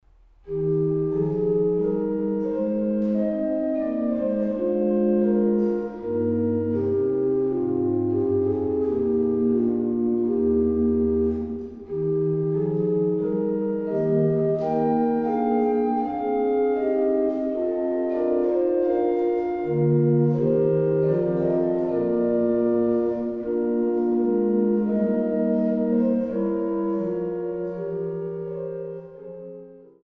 Kloß-Orgel der Kirche St. Magdalenen Langenbogen